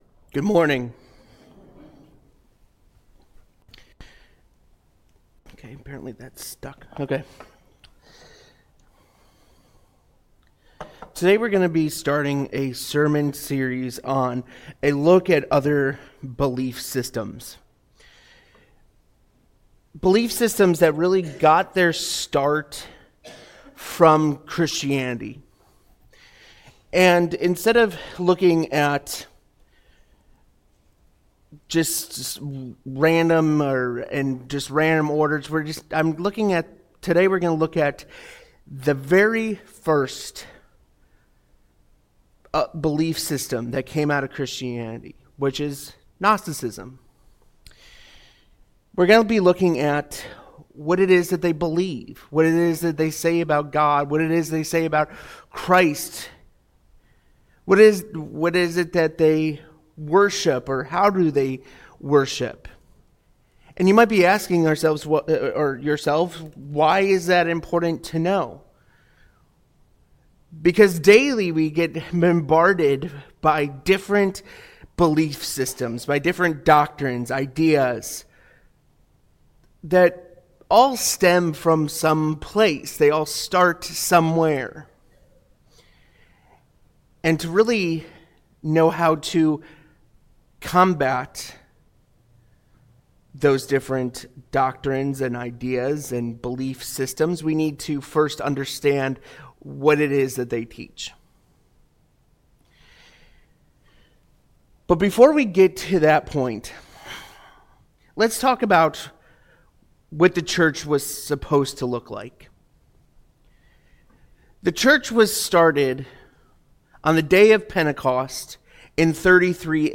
Sunday Sermons